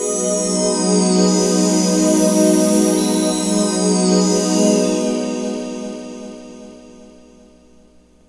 FRED PAD-L.wav